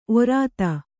♪ orāta